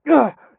m_pain_3.ogg